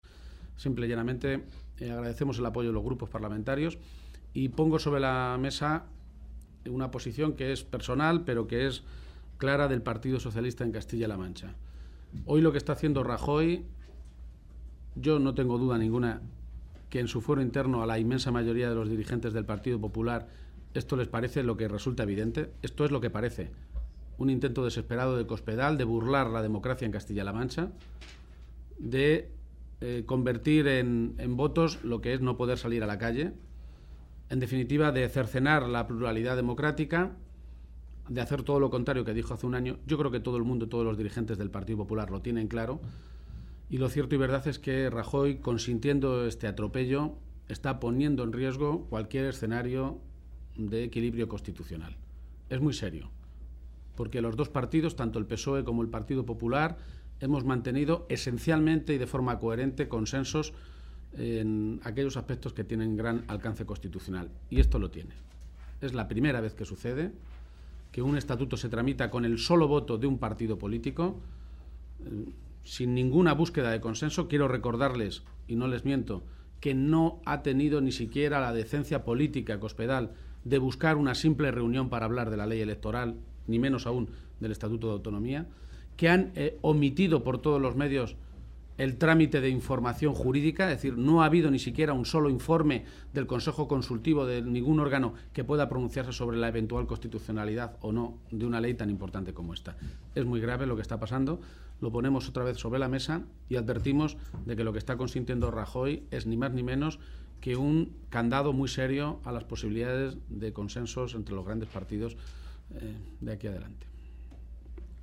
García-Page se pronunciaba de esta manera esta tarde en Madrid, en el Congreso de los Diputados, tras la votación en la Comisión Constitucional del dictamen de la Ponencia y de las enmiendas a la reforma del Estatuto de Castilla-La Mancha.
Cortes de audio de la rueda de prensa